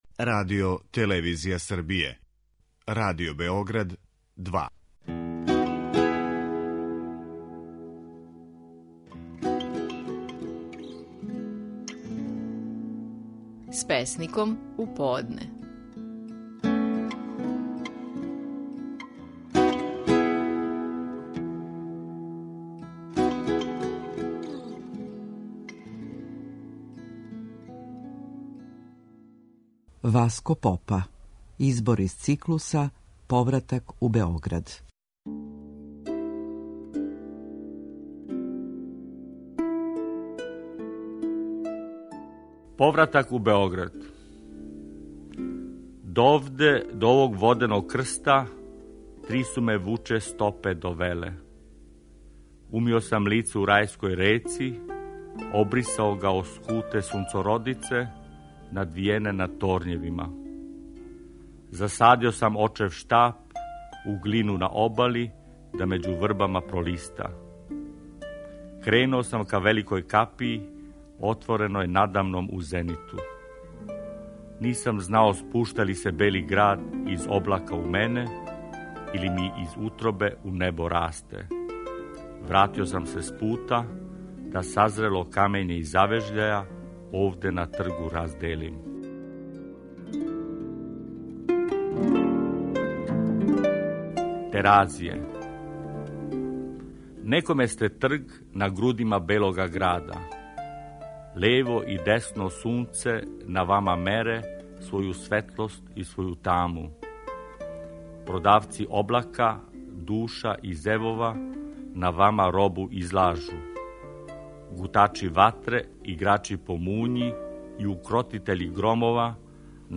Наши најпознатији песници говоре своје стихове.